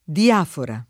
diafora
[ di- # fora ]